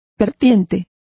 Also find out how vertientes is pronounced correctly.